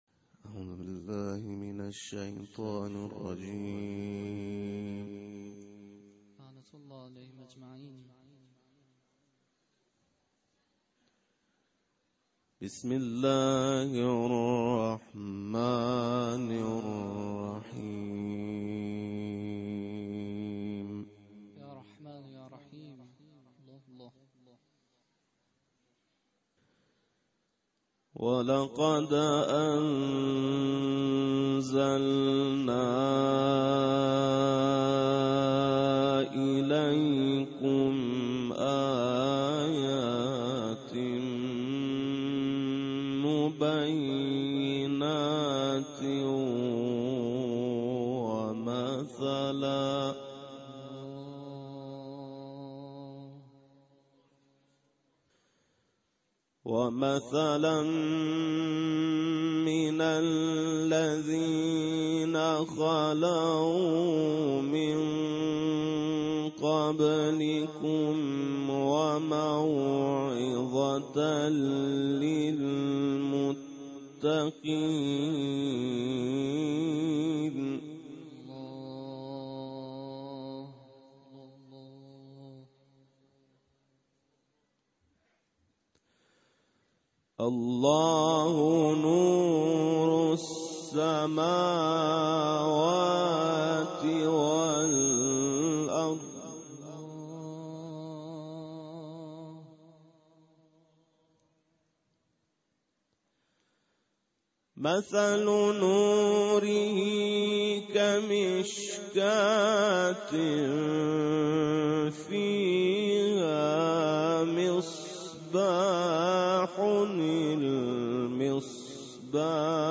قرائت